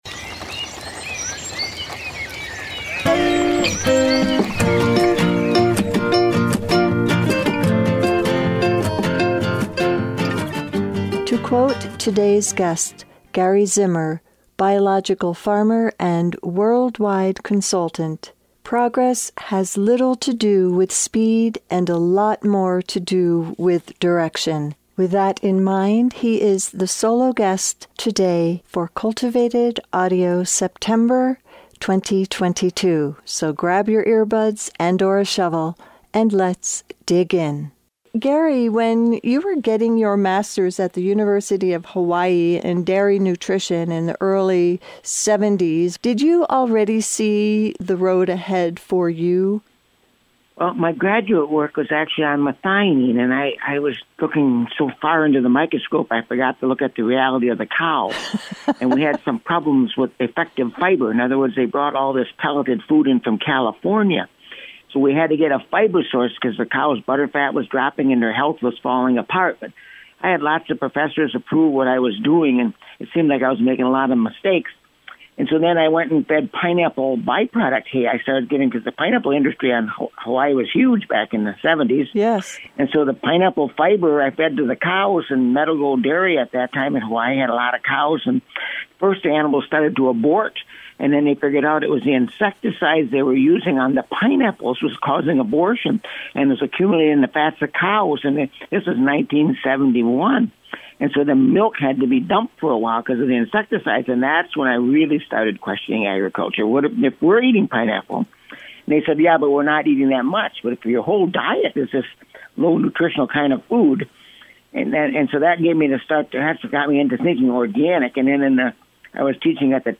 This interview was borrowed from a post from cultivated audio, my podcast on regenerative agriculture.